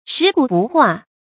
shí gǔ bù huà
食古不化发音
成语正音 不，不能读作“bú”。